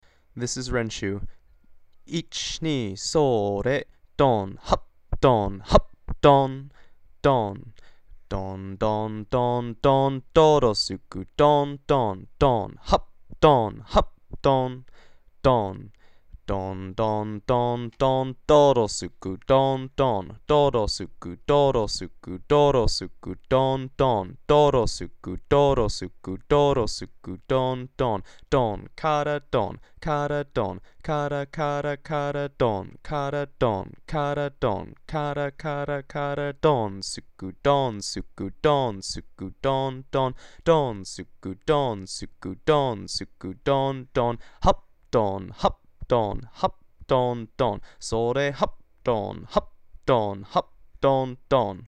Lines 1, 2, 3, and 4 are repeated twice.